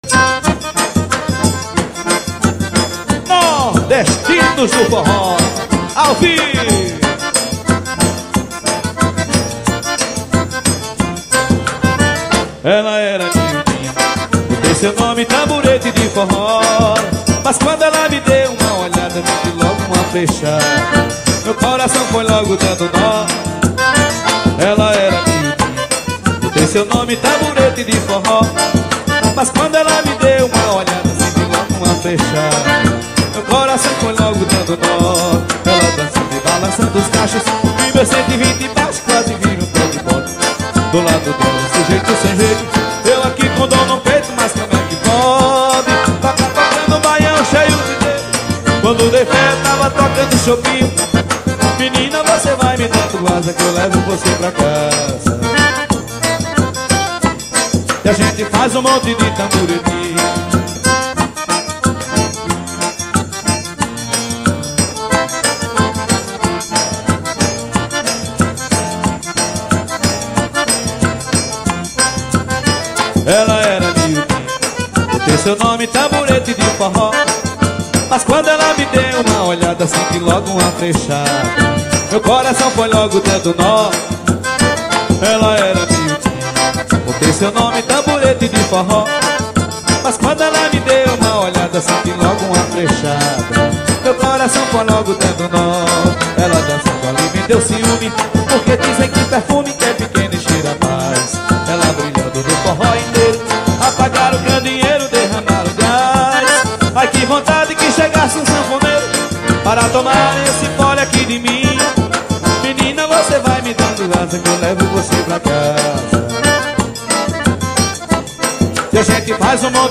2024-12-22 10:50:17 Gênero: Forró Views